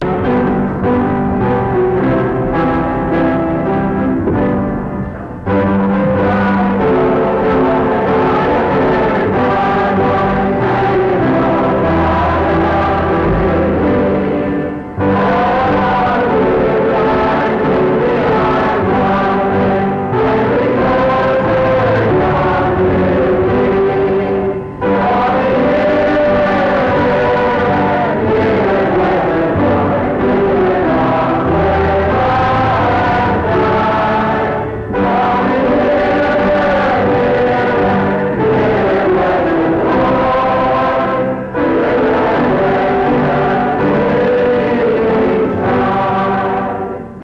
I Am Thine, Oh Lord Item 78feac2a8cc450ab228c2e89f53045b548593b62.mp3 Title I Am Thine, Oh Lord Creator Congregation Description This recording is from the Monongalia Tri-District Sing. Mount Union Methodist Church II, rural, Monongalia County, WV, track 145U.